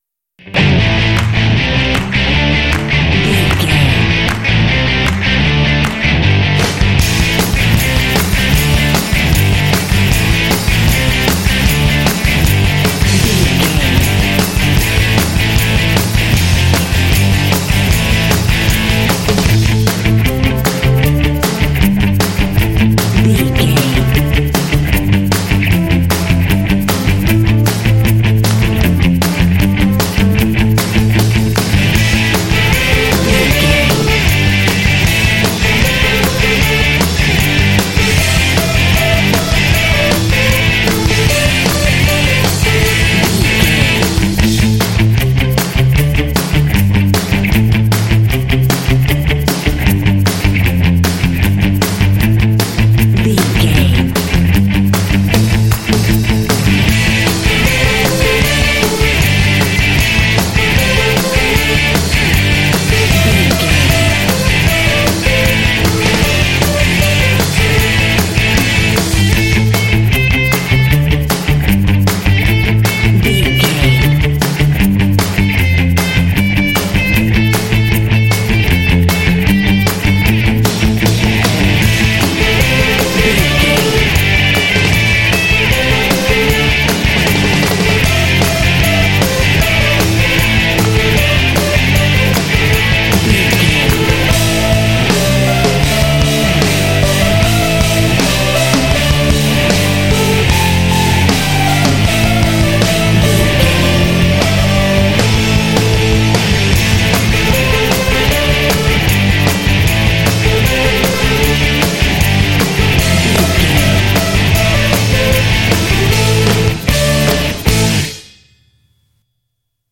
This Pop track is full of energy and drive.
Ionian/Major
driving
energetic
electric guitar
bass guitar
drums
electric piano
vocals
pop